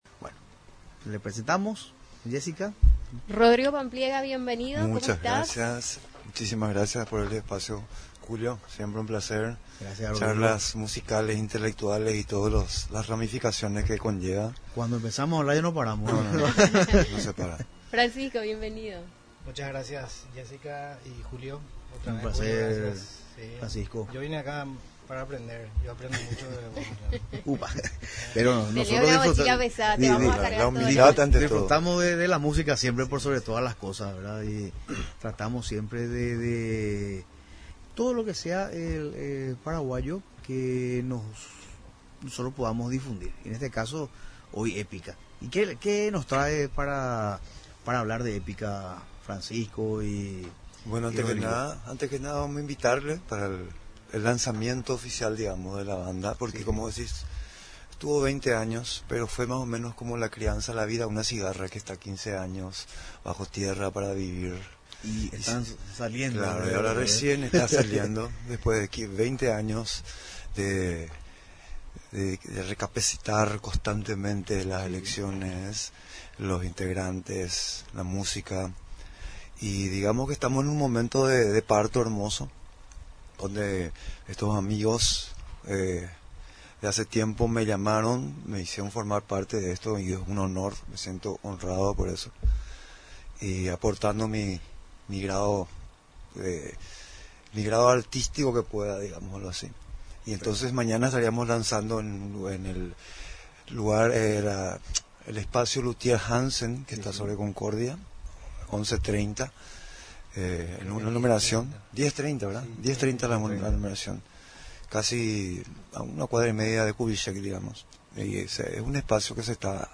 La banda paraguaya de rock Épica visita Radio Nacional del Paraguay | RADIO NACIONAL